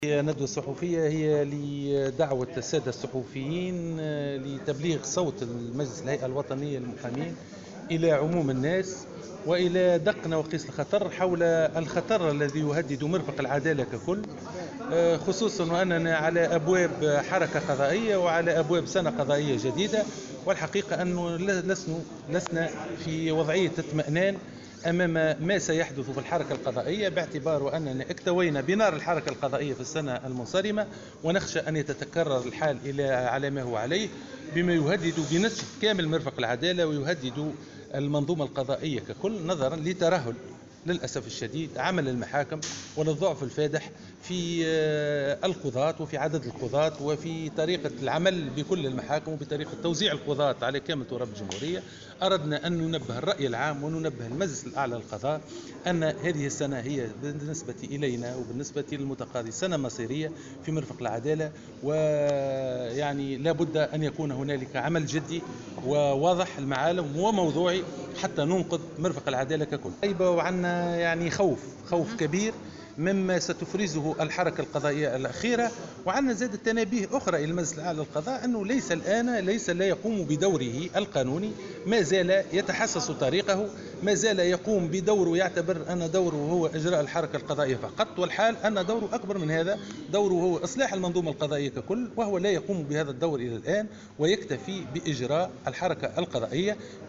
و نبّه المحرزي في تصريح اليوم لمراسل "الجوهرة أف أم" على هامش ندوة صحفية نظمها مجلس هيئة المحامين، من الخطر المحدق بالسلك القضائي لاسيما قبل انطلاق السنة القضائية الجديدة.